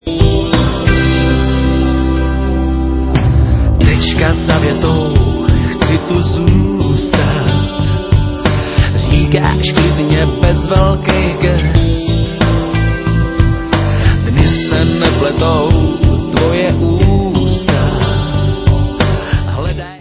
skvělými pomalými hity